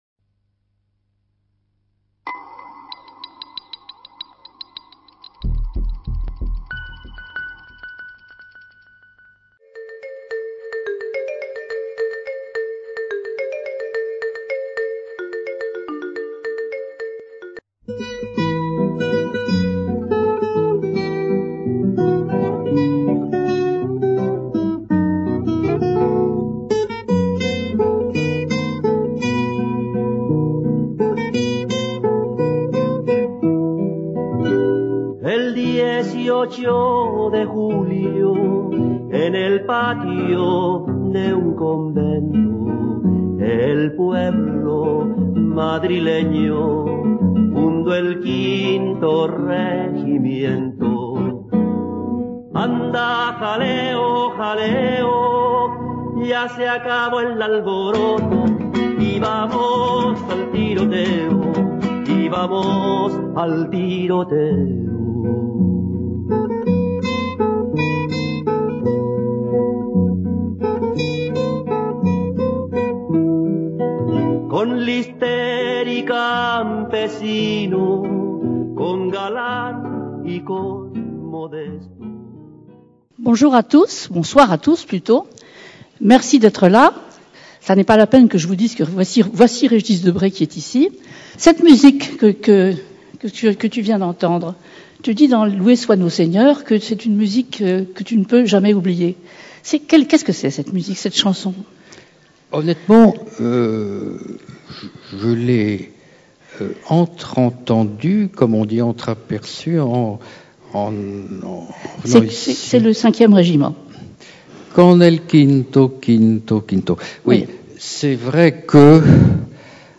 Conférence de l’Université populaire du quai Branly (UPQB), donnée le 13 décembre 2013. Rencontre avec Régis Debray, animée par Catherine Clément, dans le cadre du cycle "Grands témoins".